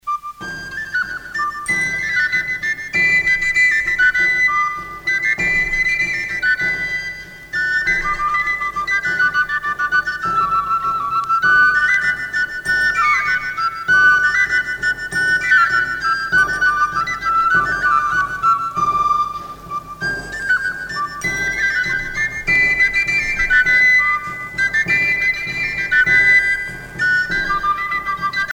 Andantino pour deux galoubets
Chants et danses traditionnelles
Pièce musicale éditée